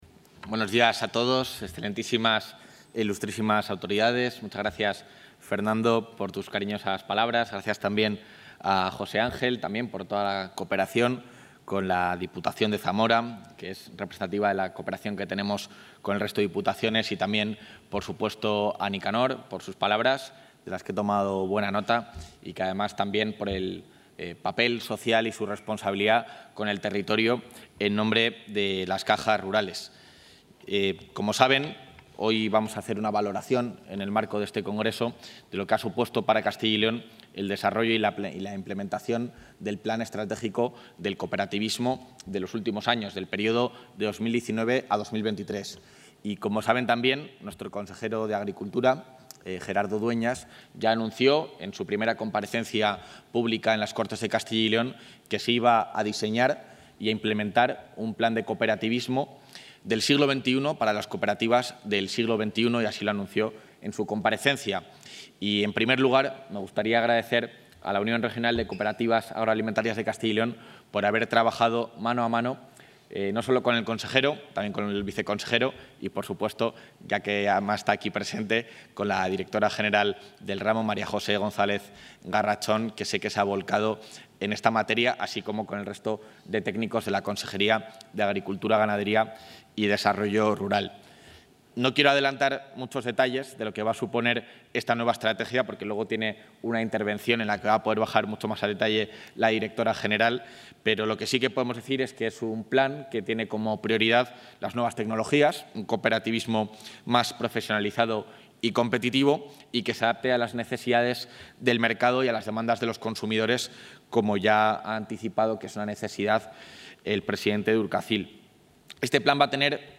Intervención del vicepresidente de la Junta.
El Plan Estratégico 2024-2027, que prevé 23 millones de inversión, ha sido presentado en el Congreso de Urcacyl, organización que ha participado activamente en su diseño